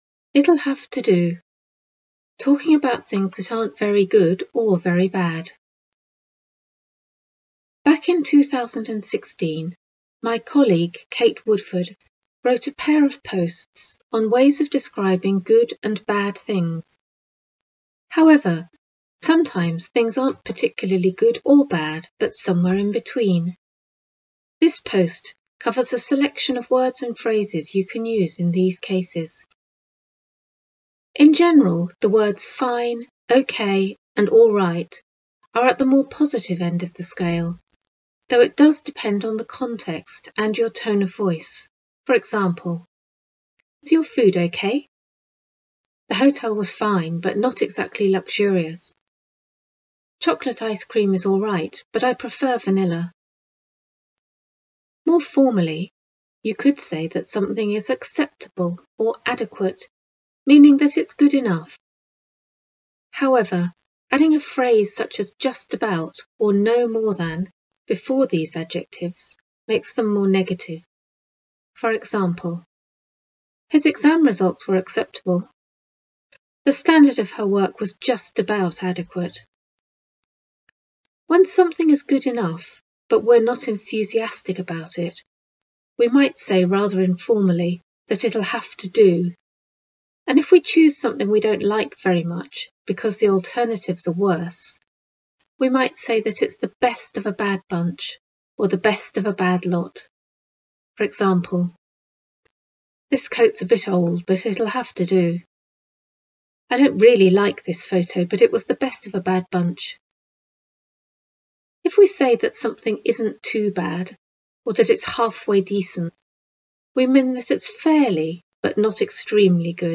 Listen to the author reading this blog post: